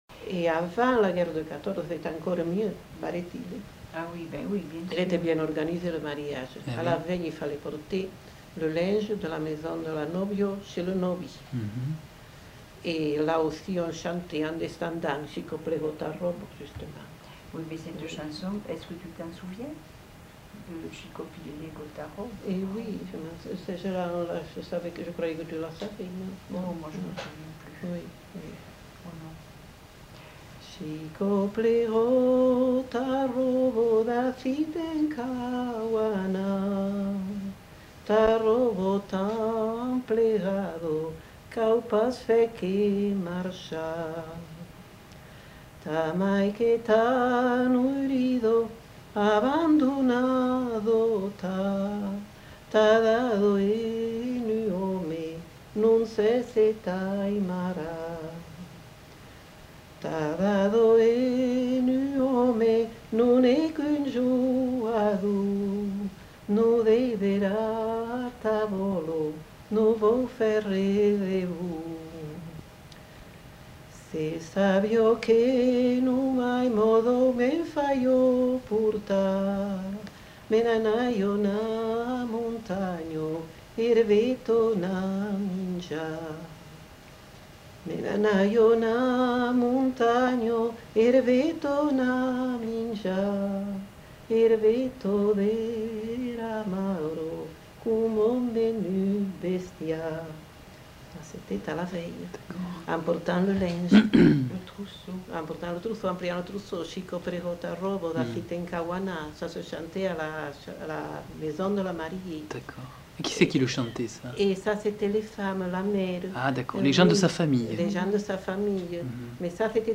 Aire culturelle : Couserans
Lieu : Aulus-les-Bains
Genre : chant
Effectif : 1
Type de voix : voix de femme
Production du son : chanté